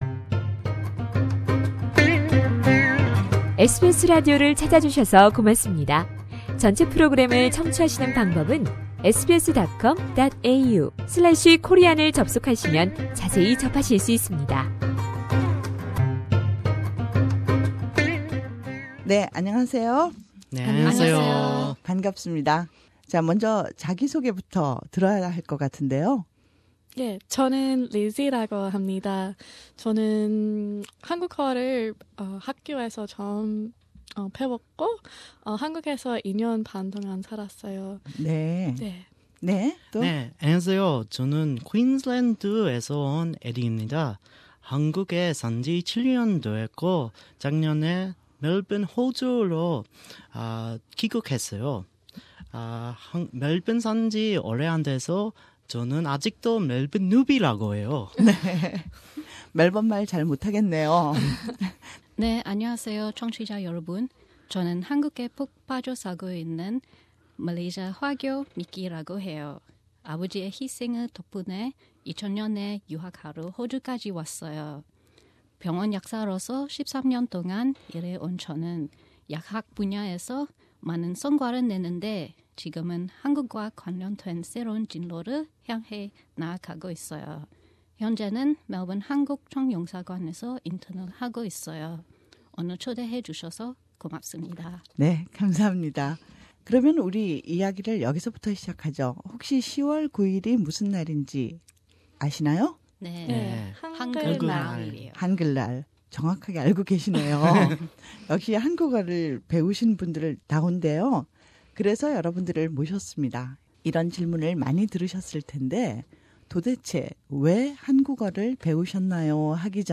As we celebrate the 2016 annual Hangeul day, three Australians with diverse heritage background in Melbourne met together to talk about their love and passion for Korean language and culture.